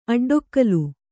♪ aḍḍokkalu